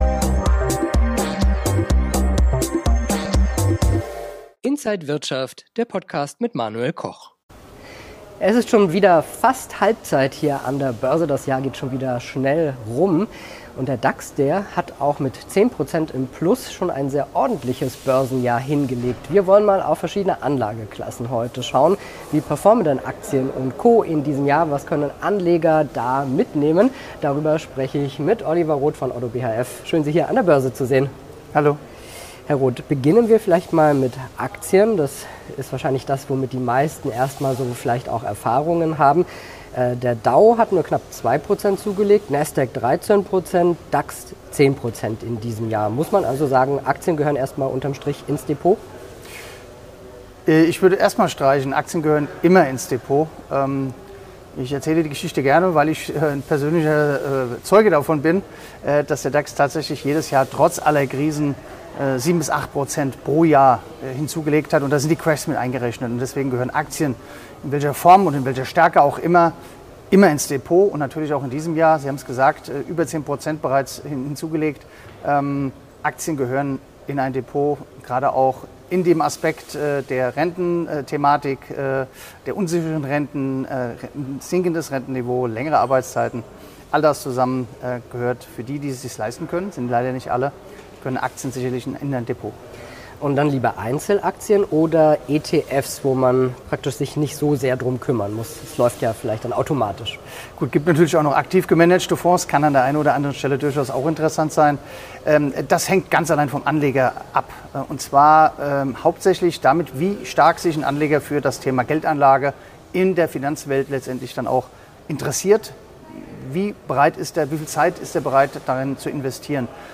Interview
an der Frankfurter Börse